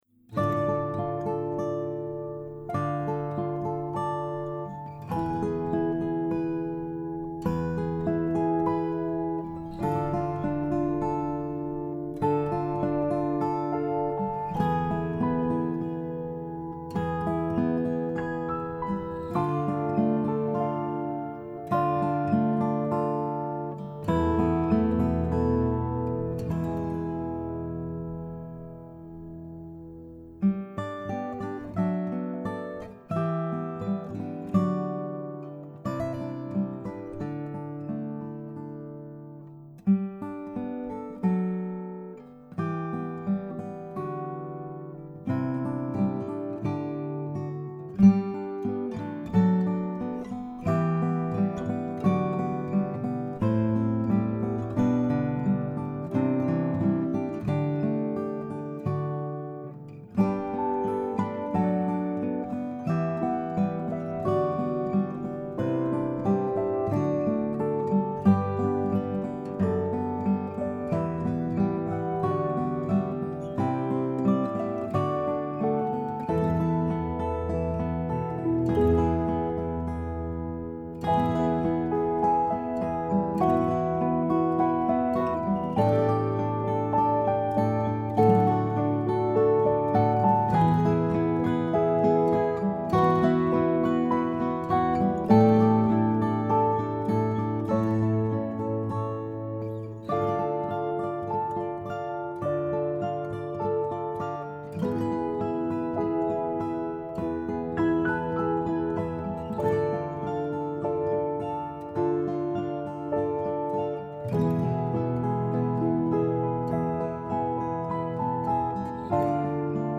Retreat Solo Piano